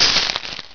claw.wav